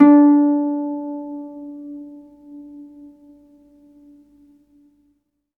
HARP EN4 SUS.wav